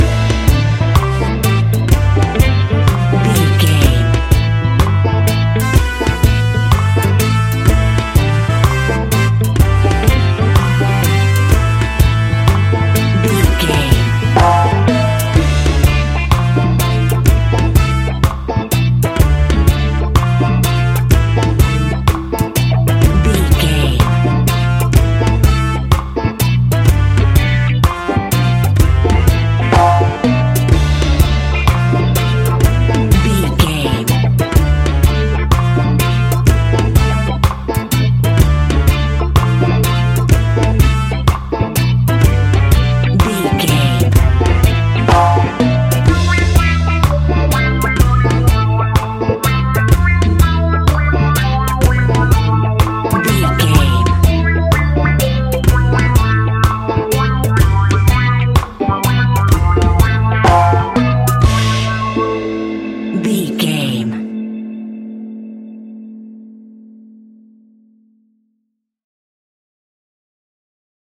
Aeolian/Minor
laid back
chilled
off beat
drums
skank guitar
hammond organ
percussion
horns